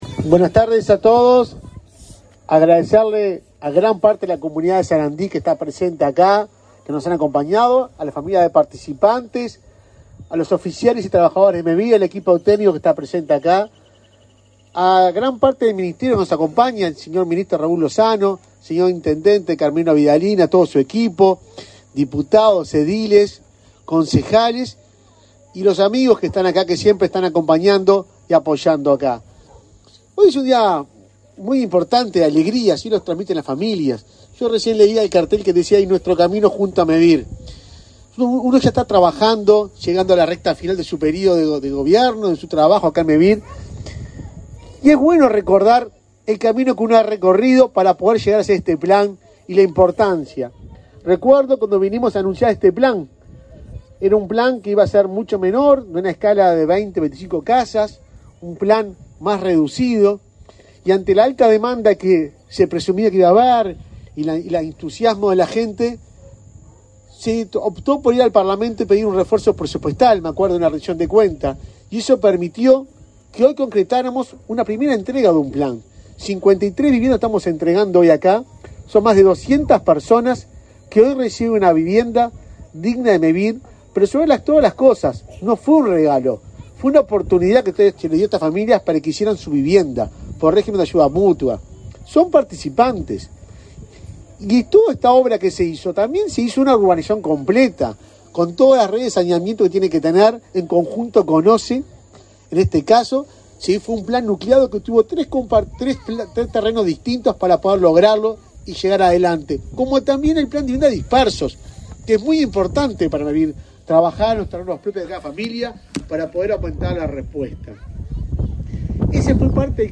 Acto de inauguración de soluciones habitacionales de Mevir en Sarandí del Yí
En la ceremonia, disertaron el presidente de Mevir, Juan Pablo Delgado, y el ministro de Vivienda y Ordenamiento Territorial, Raúl Lozano.